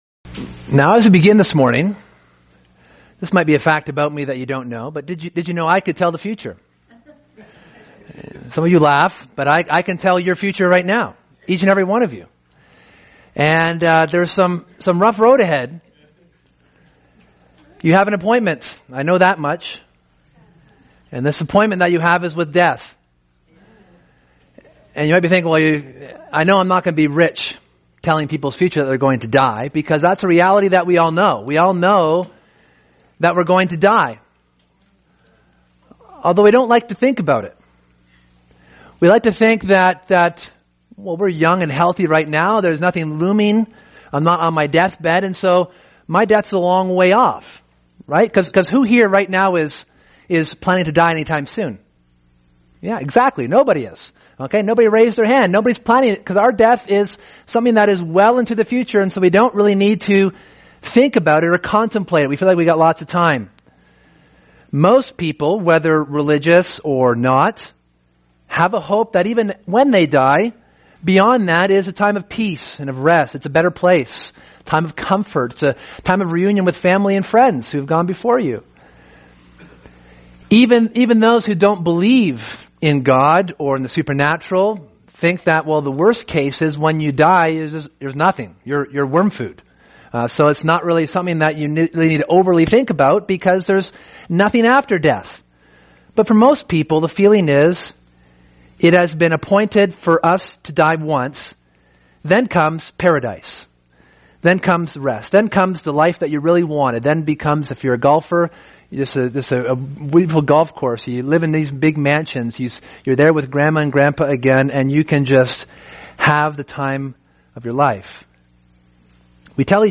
Sermon: When You Die...